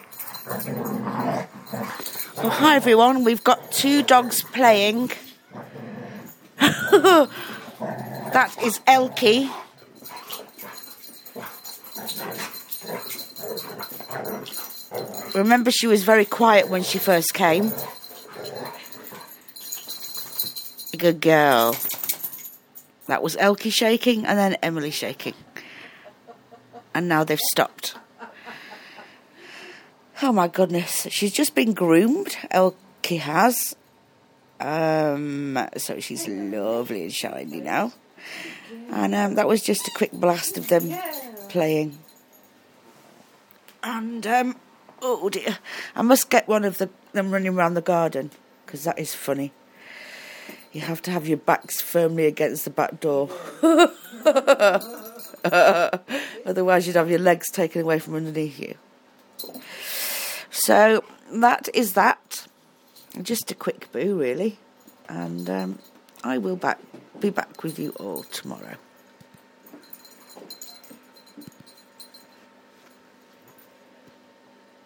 two very noisy dogs playing